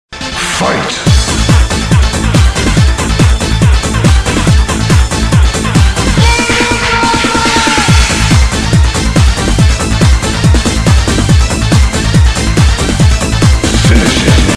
Музыкальная заставка